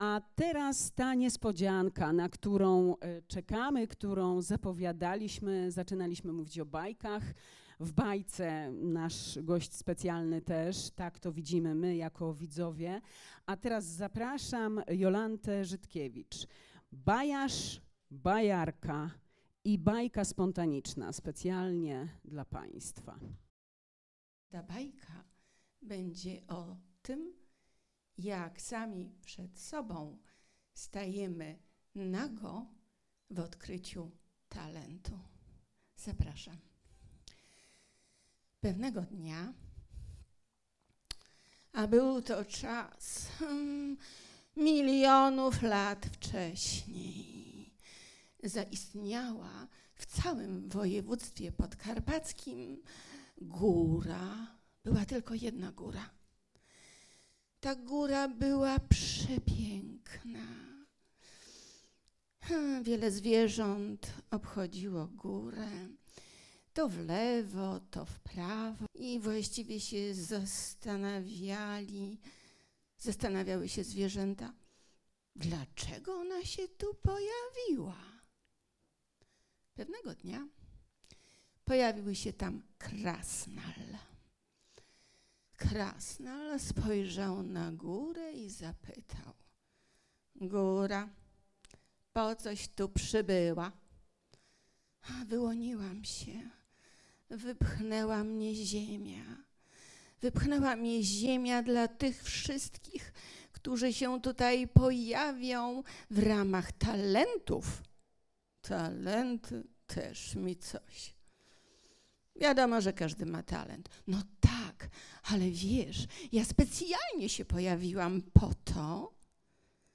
Posłuchaj Bajki
Nagranie zarejestrowane podczas III Bieszczadzkiego FORUM Kobiet